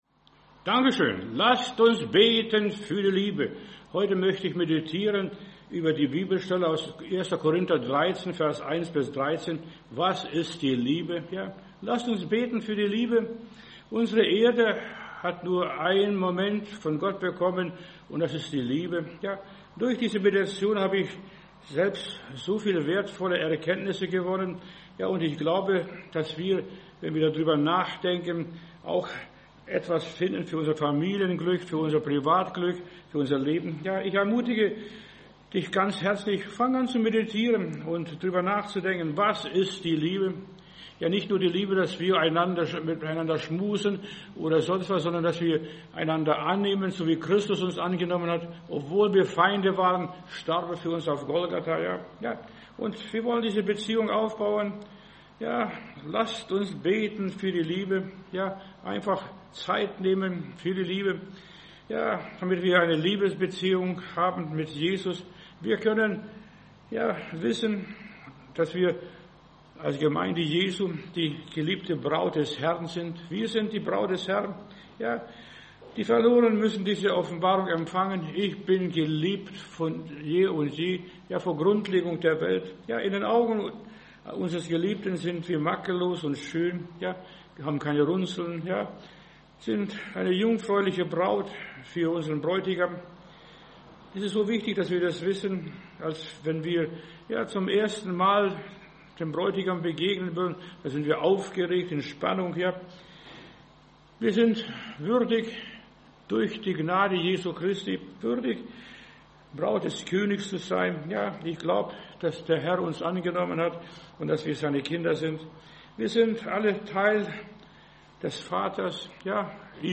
Predigt herunterladen: Audio 2025-09-20 Die Liebe Video Die Liebe